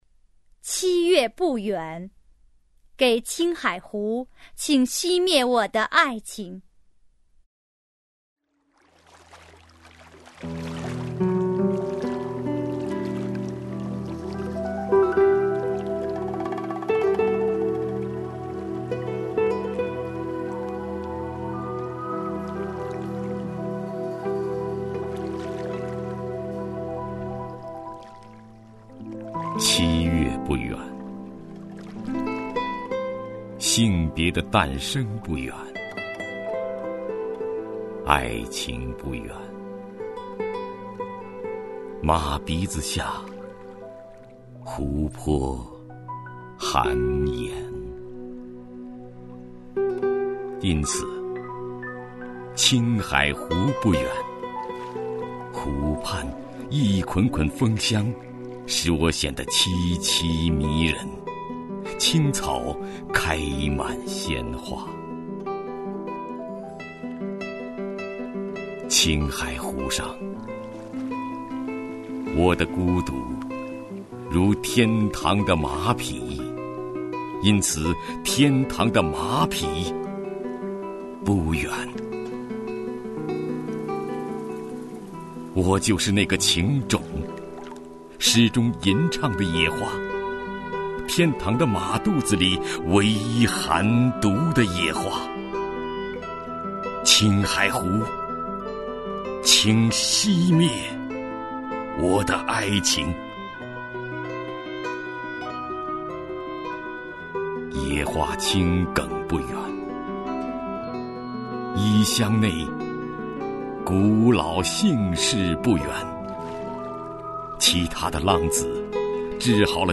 首页 视听 名家朗诵欣赏 徐涛
徐涛朗诵：《七月不远——给青海湖，请熄灭我的爱情》(海子)